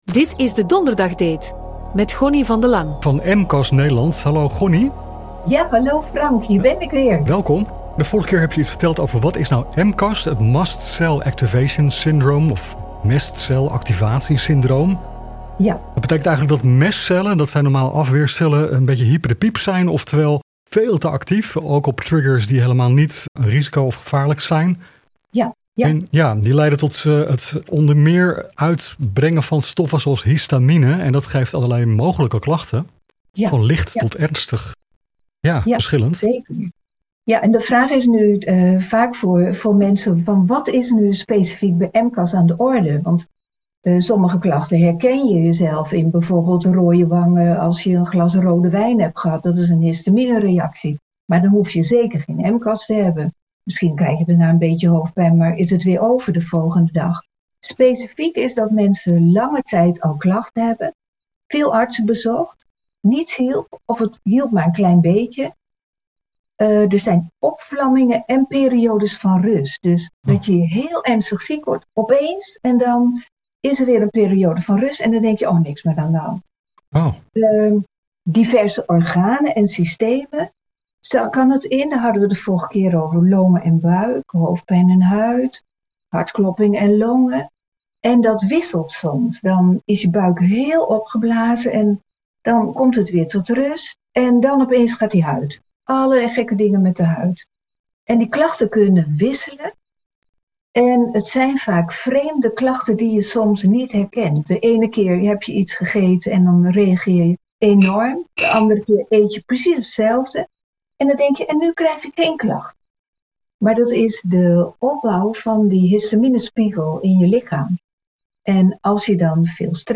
Complementair therapeut